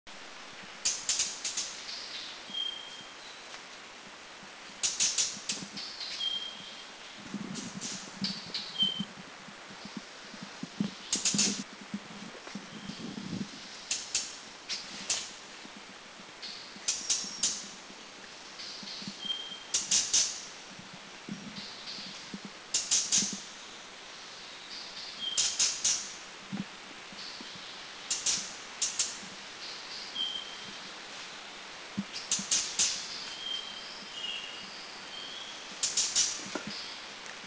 Hill Blue Flycatcher
HillBlueFlycatcher.mp3